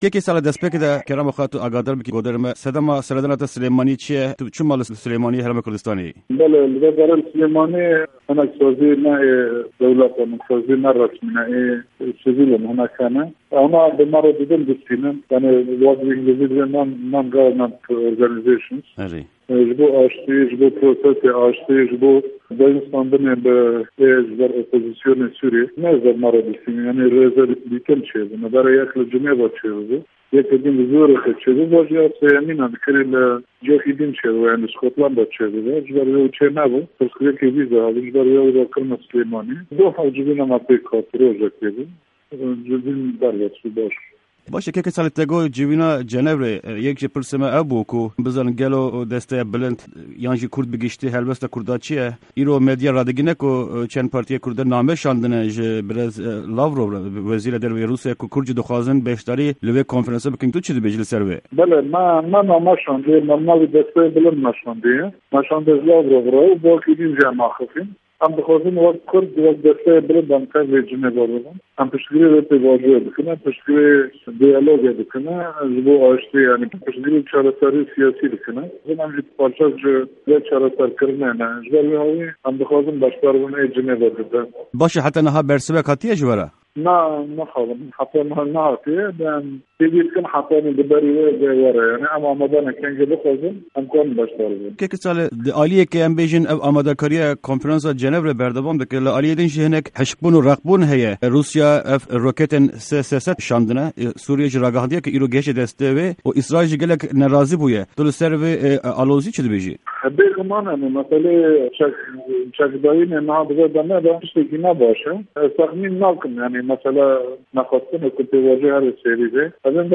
Hevpeyvîn_Salih_Muslim_05_30_13_RR